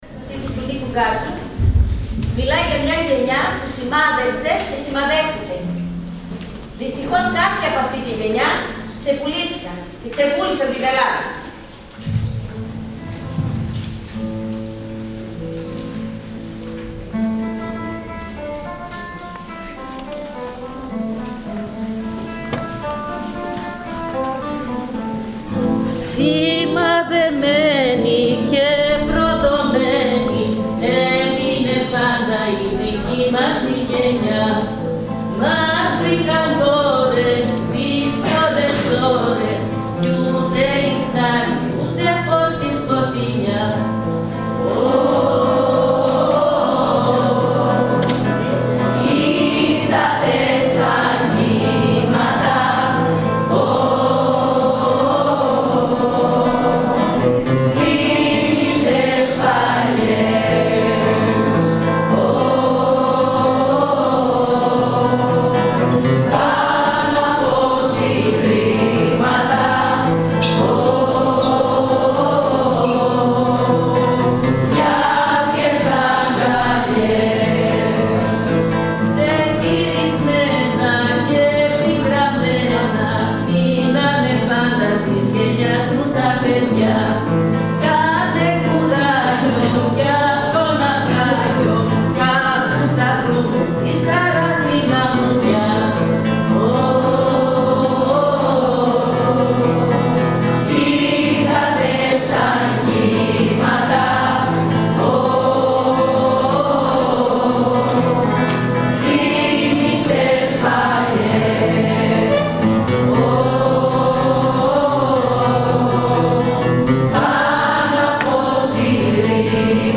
Μια καταπληκτική γιορτή μνήμης για τη 17 Νοέμβρη διοργάνωσαν οι μαθητές του σχολείου μας μαζί με τους καθηγητές τους. Πάρτε μια γεύση ακούγοντας την χορωδία.